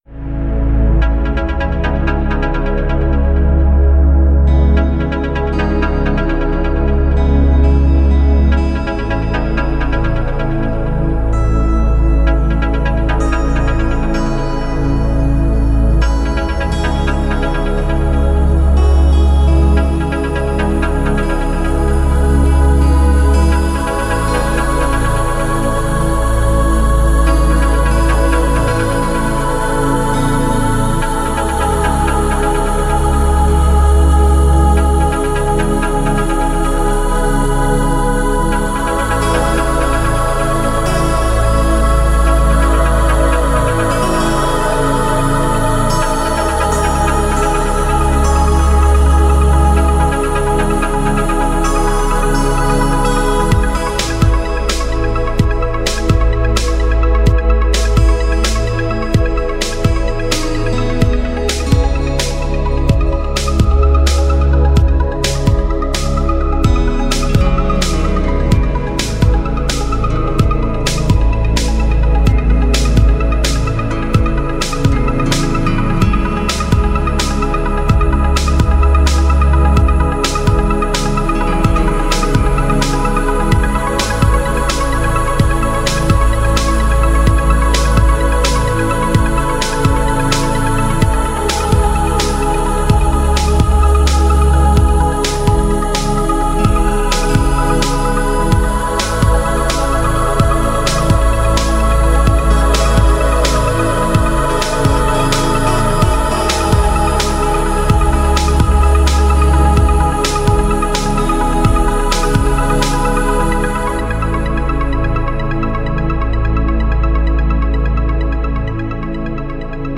• Качество: 182, Stereo
гитара
мелодичные
спокойные
без слов
красивая мелодия
chillout
расслабляющие
Trance
Ambient
Прелестная транс и эмбиент музыка